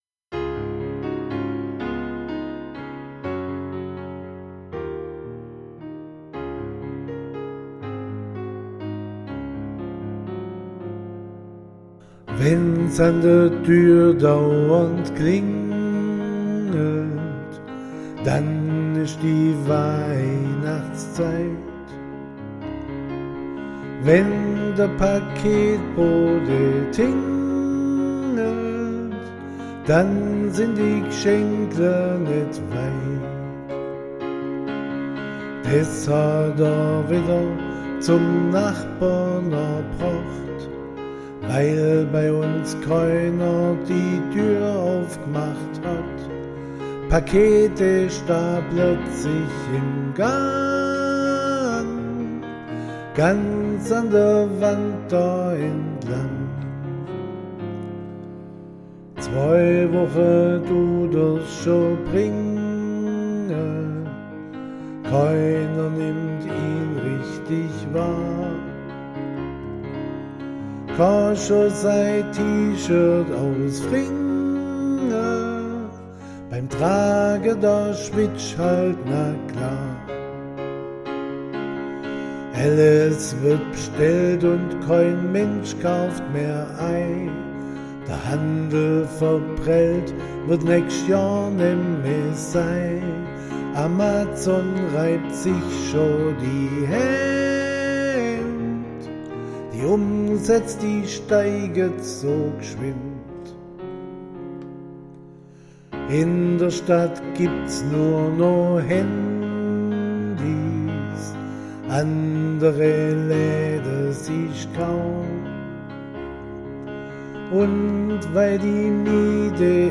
Sie haben schon wieder ein Weihnachtslied geschrieben
Schwäbisch först.